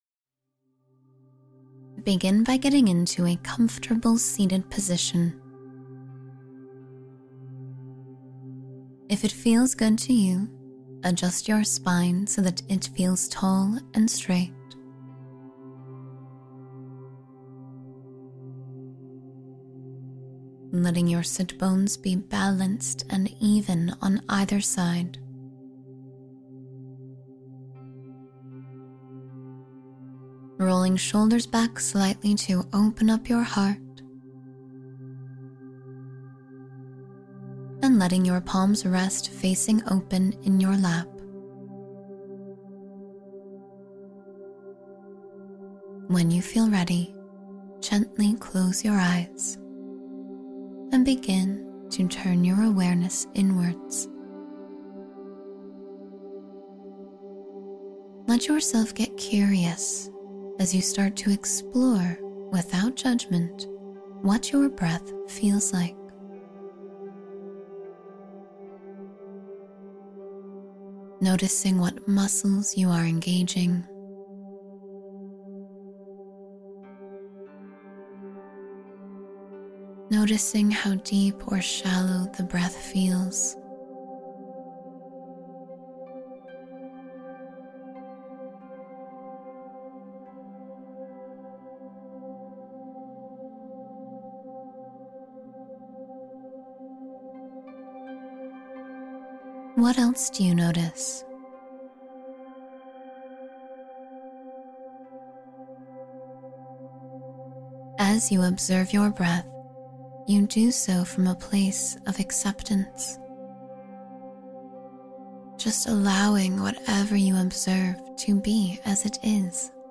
Ten Minutes to Calm Focus Through Mindfulness — Guided Practice for Clarity